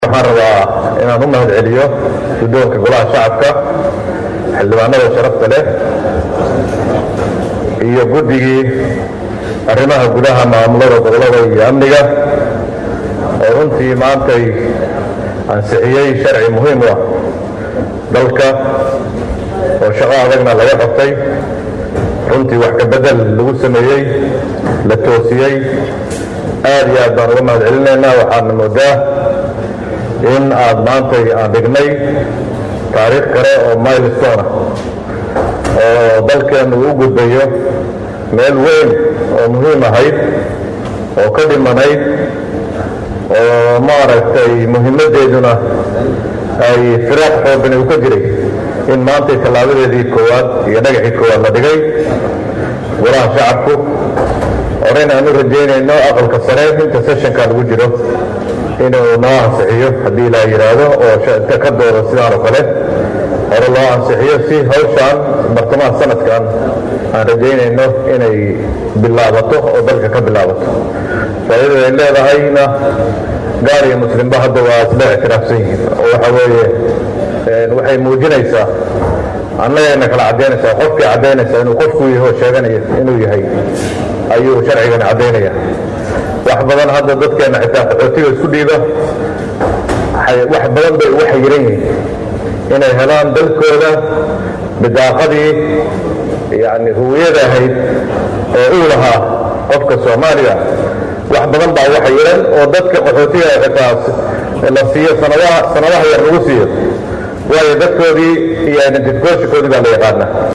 Wasiirka wasaaradda arrimaha gudaha ee dalka Soomaaliya Axmad Macallin Fiqi ayaa sheegay in sanadkan gudihiisa la billaabi doona qaadashada aqoonsiga muwaadinka ee loo yaqaano National ID. Hadalkan ayuu ka sheegay kal-fadhigii maanta ee golaha shacabka ee baarlamaanka dalka Soomaaliya.